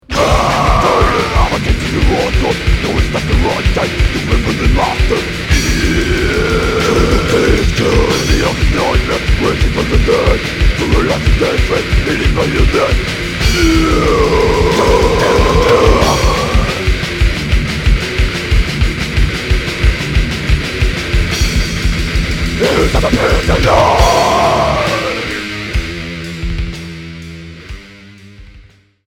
Trash métal